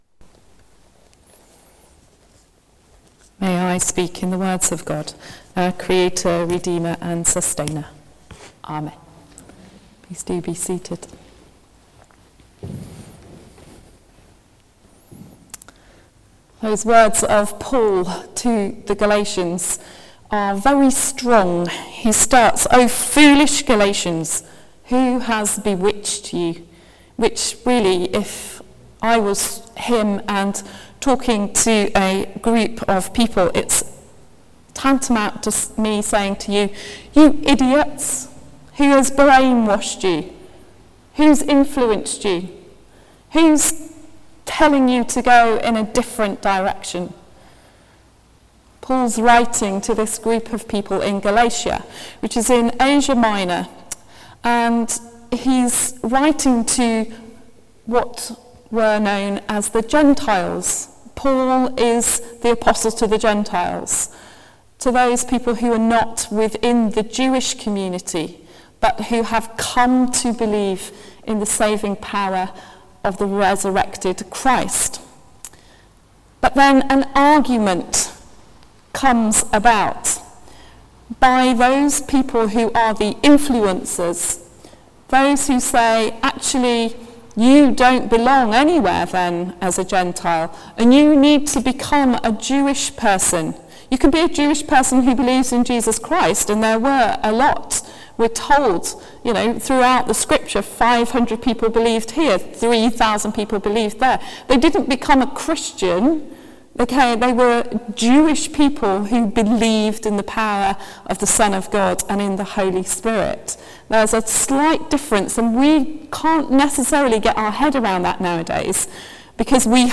Sermon: Spirit and the Law | St Paul + St Stephen Gloucester